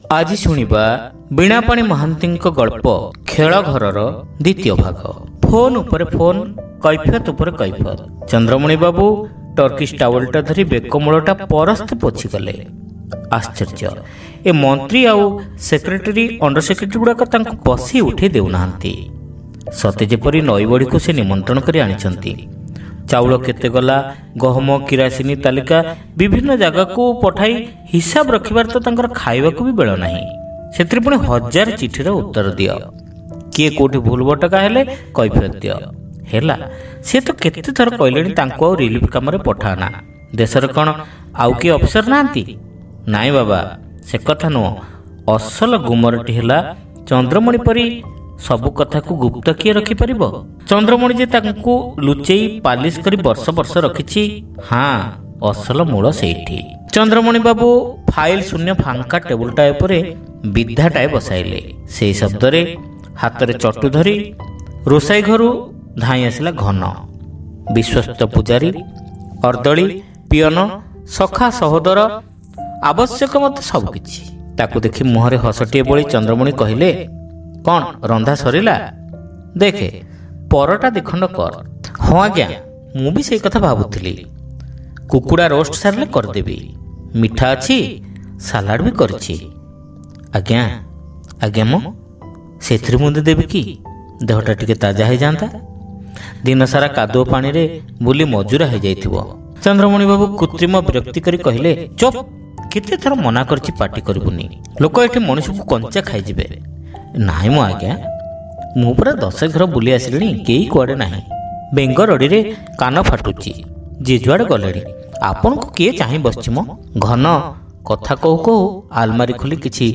Audio Story : Khelaghara (Part-2)